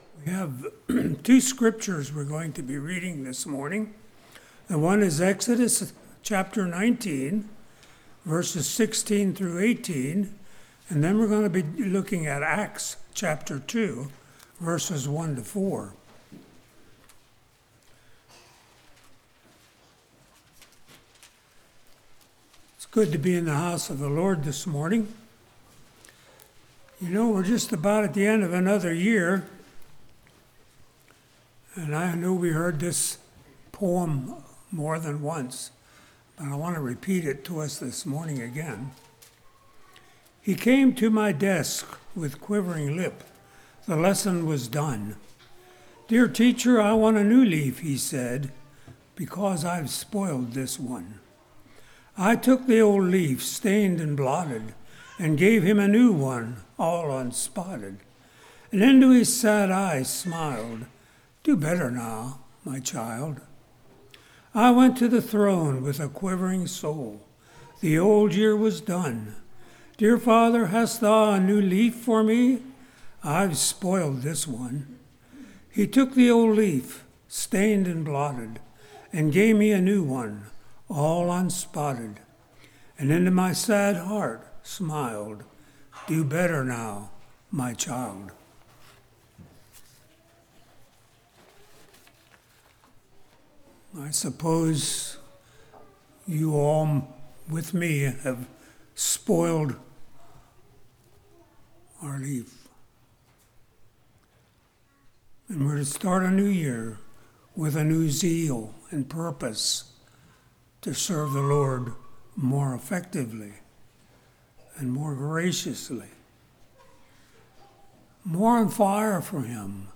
Acts 2:1-4 Service Type: Morning Phenomenon of Fire Fire Gives Light Fire Needs Fuel « For What is Your Life?